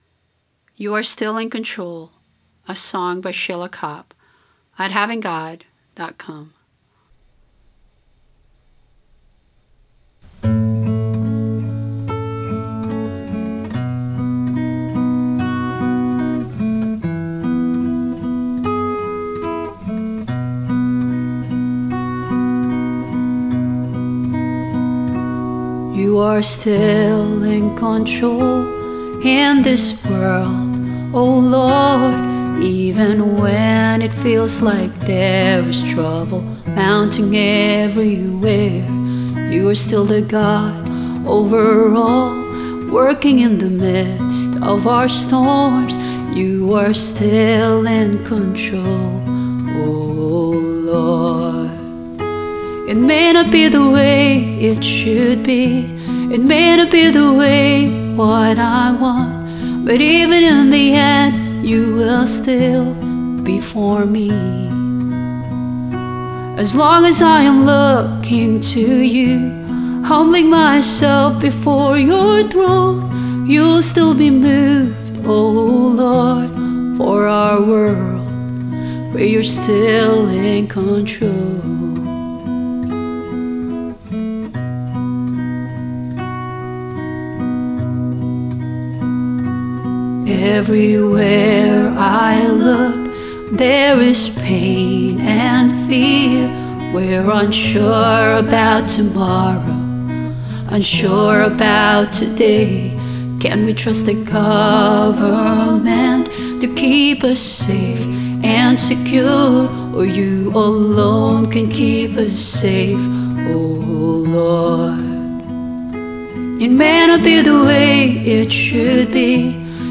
Vocals and guitar